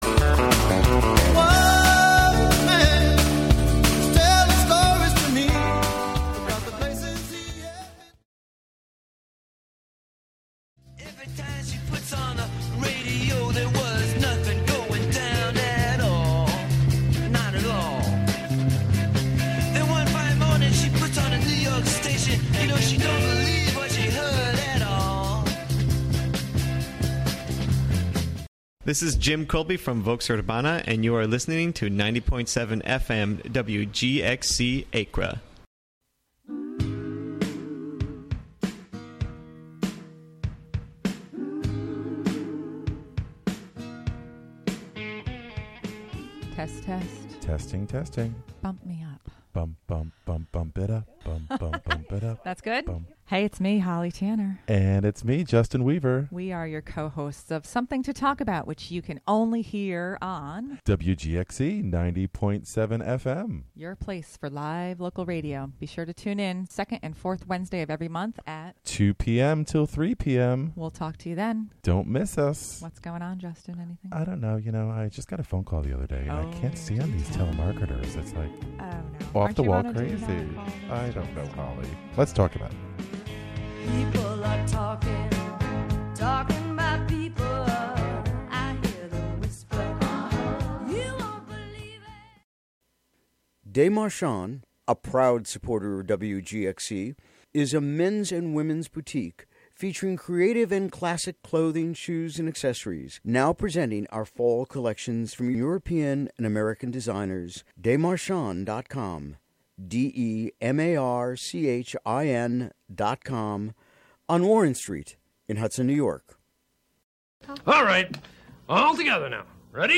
"All Together Now!" is a daily news show brought to you by WGXC-FM in Greene and Columbia counties. The show is a unique, community-based collaboration between listeners and programmers, both on-air and off. "All Together Now!" features local and regional news, weather updates, feature segments, and newsmaker interviews.